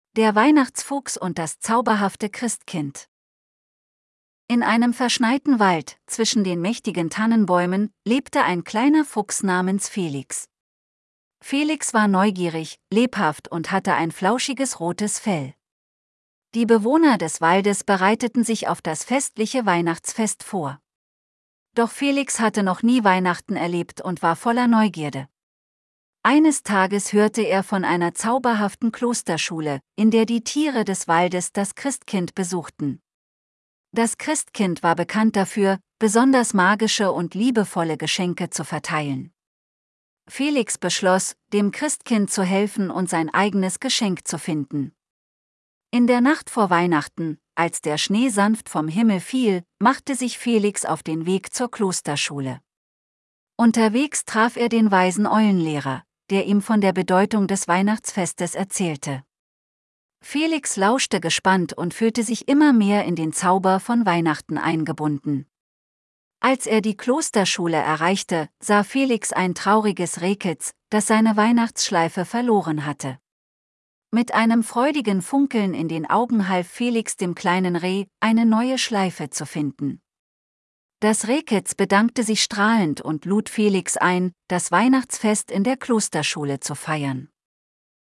Geschichte, Bild und Voiceover generiert von GenAI.